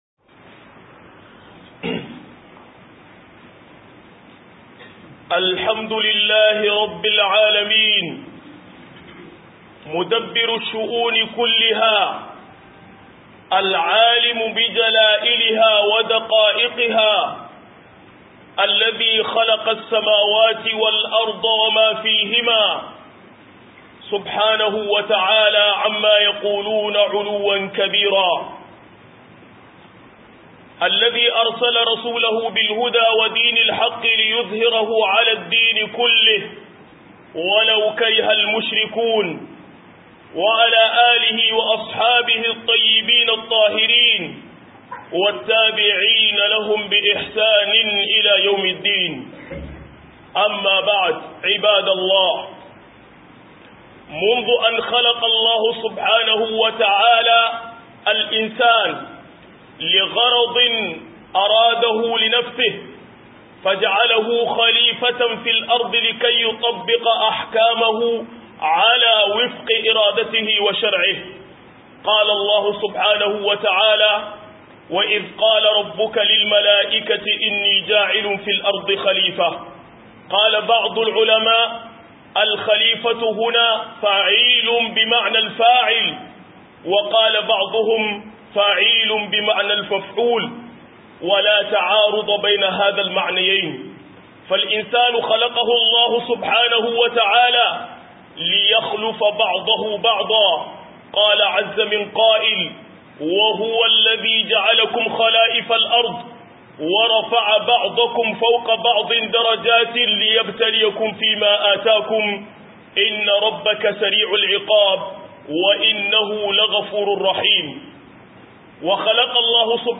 99-Al amn larab - MUHADARA